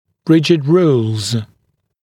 [‘rɪʤɪd ruːlz][‘риджид ру:лз]жесткие правила